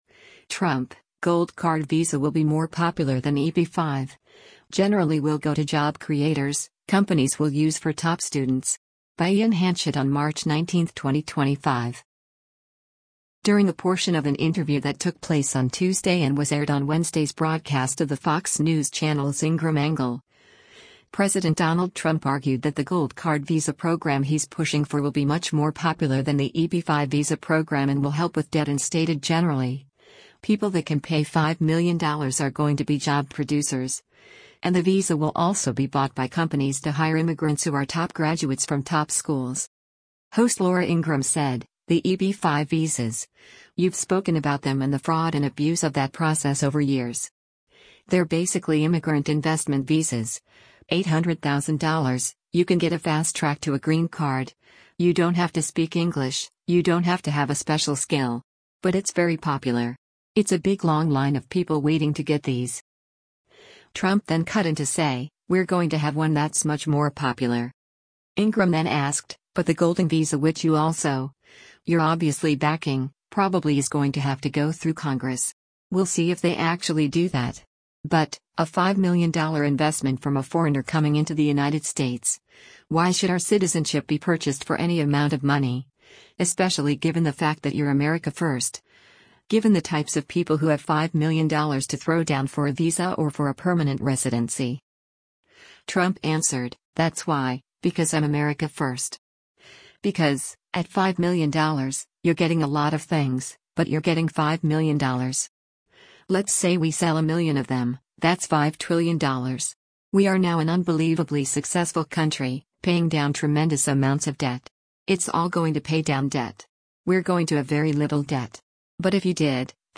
During a portion of an interview that took place on Tuesday and was aired on Wednesday’s broadcast of the Fox News Channel’s “Ingraham Angle,” President Donald Trump argued that the gold card visa program he’s pushing for will be “much more popular” than the EB-5 visa program and will help with debt and stated “generally, people that can pay $5 million are going to be job producers,” and the visa will also be bought by companies to hire immigrants who are top graduates from top schools.